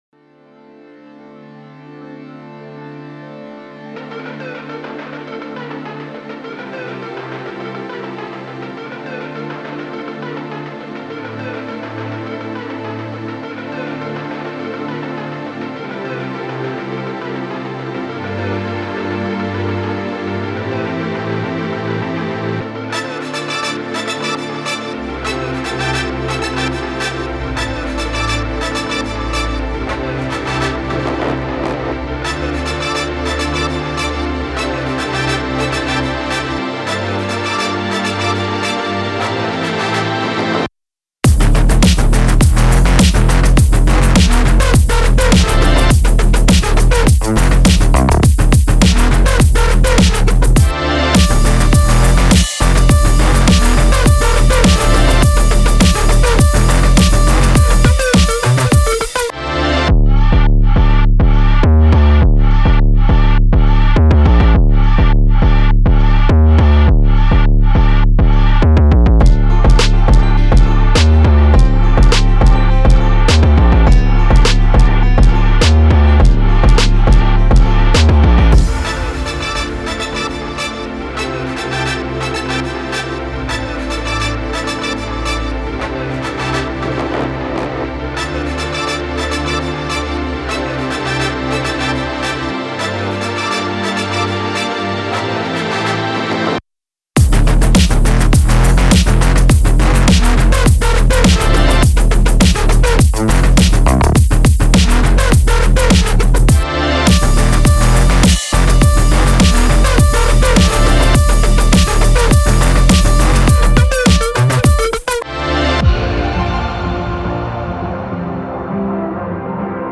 2026 in K-Pop Instrumentals